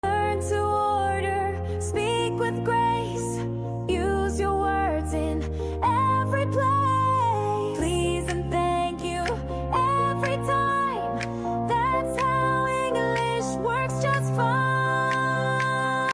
🍽 Learn English at a Restaurant — With Music! Want to order food in English with confidence? This fun song teaches you real-life restaurant phrases in a simple and memorable way — perfect for A1–A2 learners and ESL students!
🎵 Learn real English through rhythm and repetition.